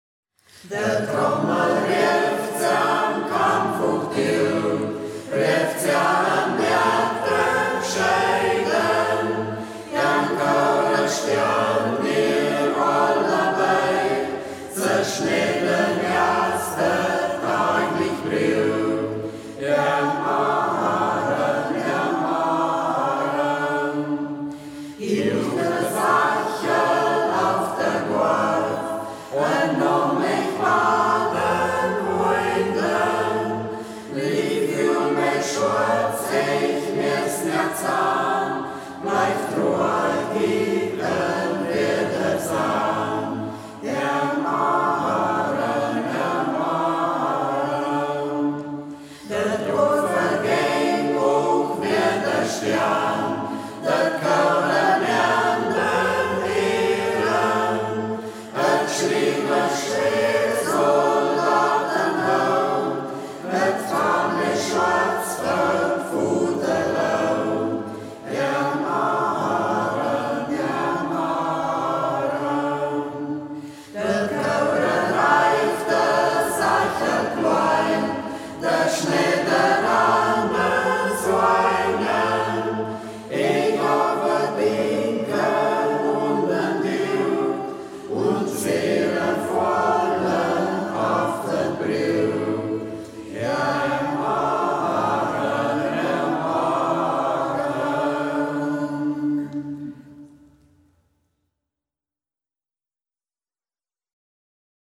Singkreis Kampestweinkel • Ortsmundart: Braller • 1:44 Minuten • Herunterladen